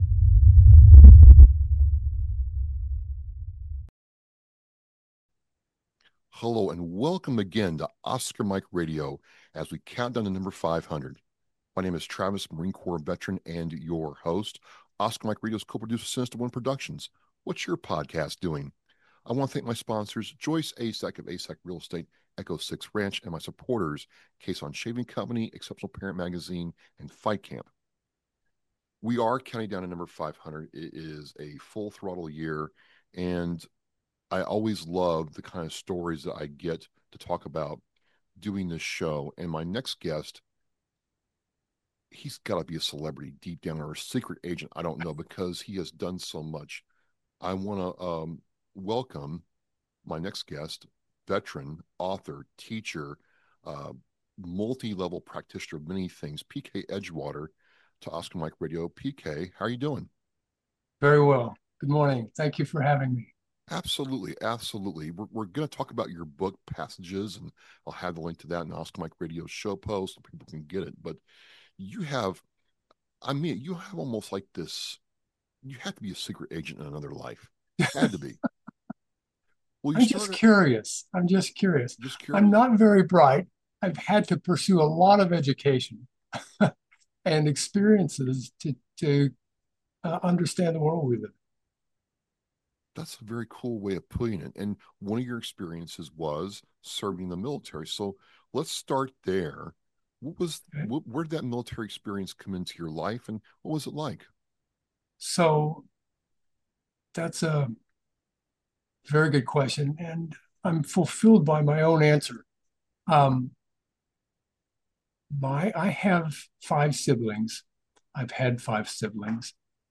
Veteran Stories, Trauma, and Healing: A Conversation